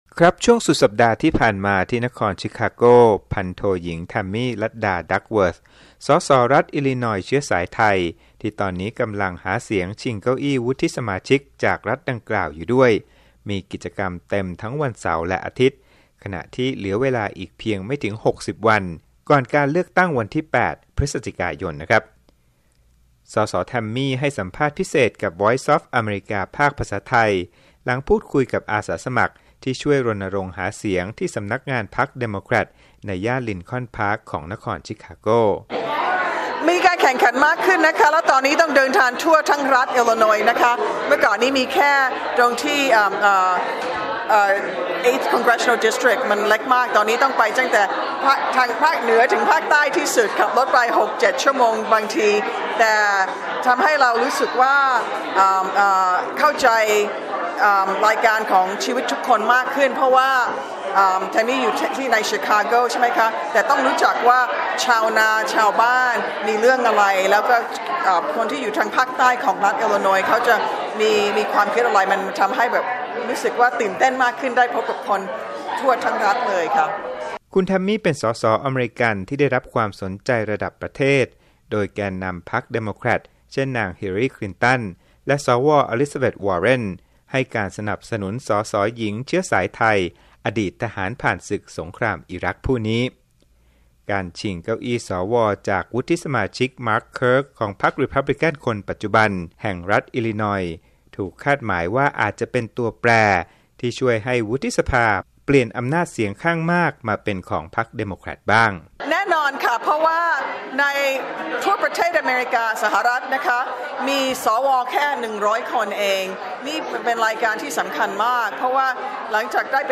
ส.ส.แทมมี่ ให้สัมภาษณ์พิเศษกับวีโอเอไทย หลังพูดคุยกับอาสาสมัครที่ช่วยรณรงค์หาเสียงที่สำนักงานพรรคเดโมเครตในย่านลินคอนพาร์คของนครชิคาโก